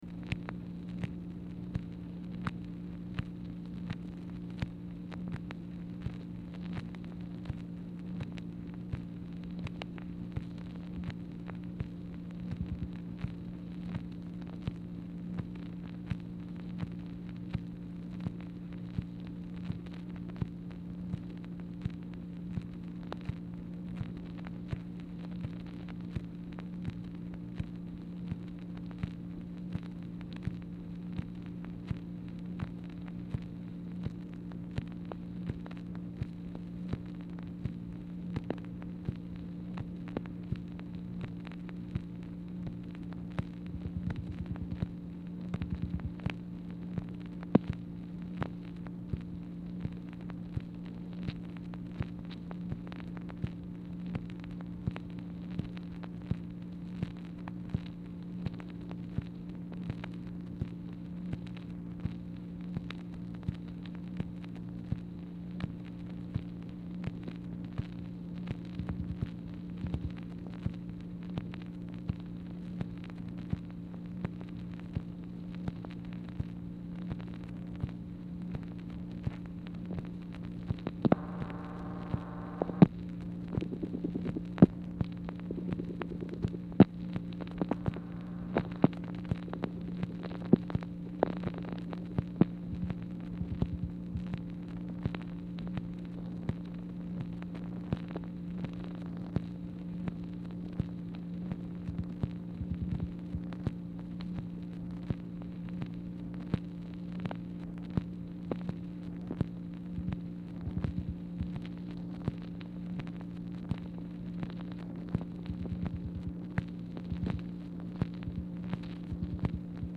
Telephone conversation # 3015, sound recording, MACHINE NOISE, 4/13/1964, time unknown | Discover LBJ
Format Dictation belt